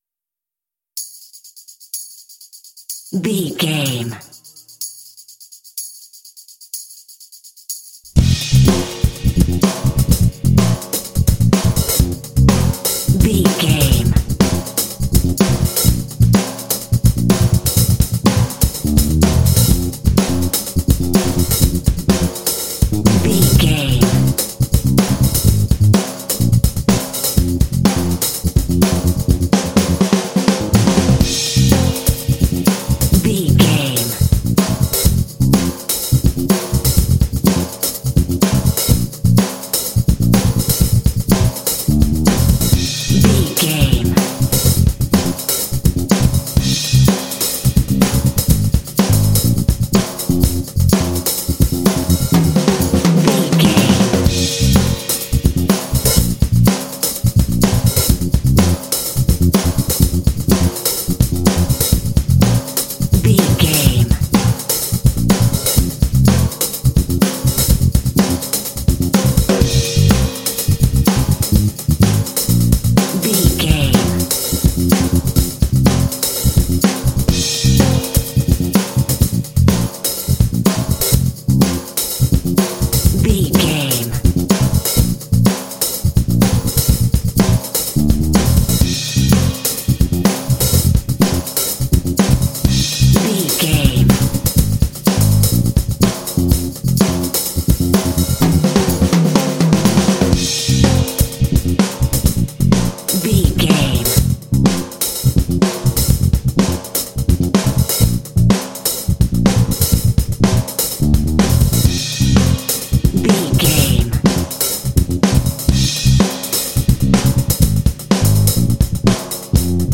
This funk track is reminiscent of 12-bar blues phrasing.
Uplifting
Ionian/Major
groovy
funky
driving
energetic
percussion
drums
bass guitar
70s